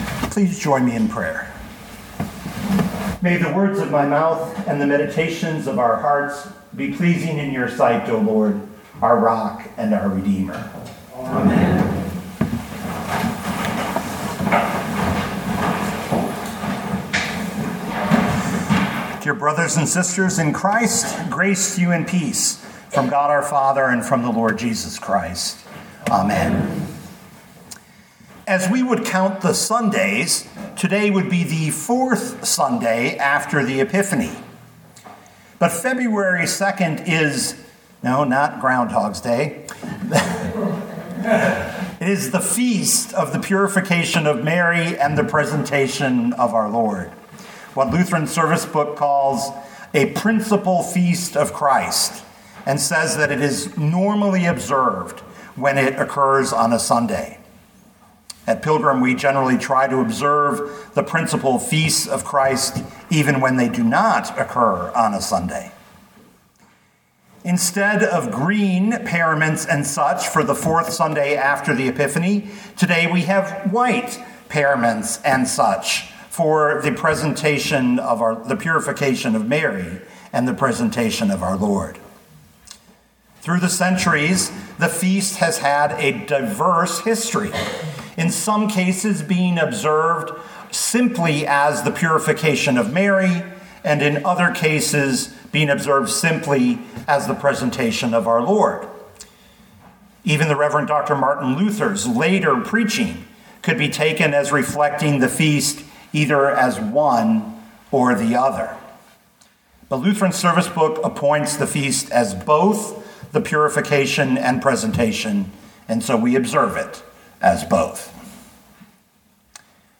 2025 Luke 2:22-40 Listen to the sermon with the player below, or, download the audio.